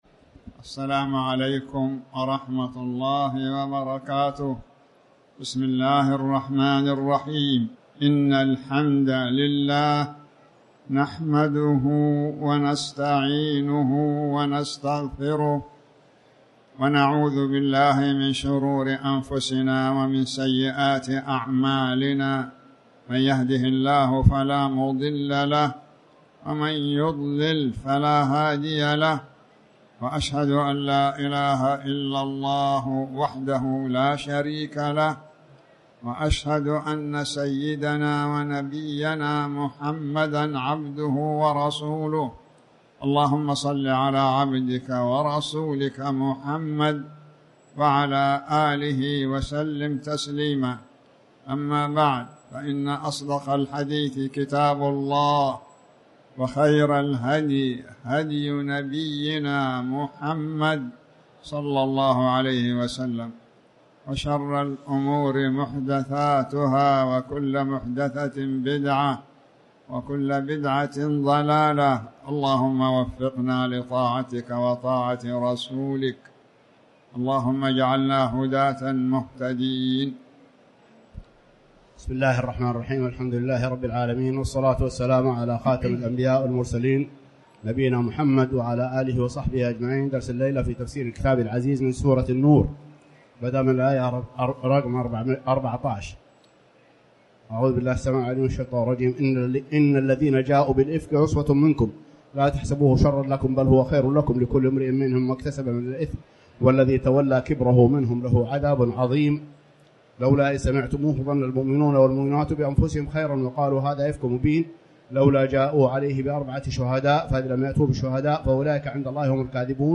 تاريخ النشر ١٨ ربيع الأول ١٤٤٠ هـ المكان: المسجد الحرام الشيخ